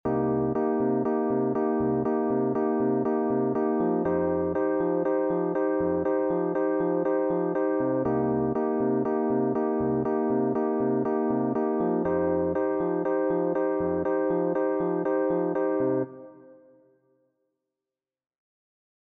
Ostinato main droite
ostinato_md.mp3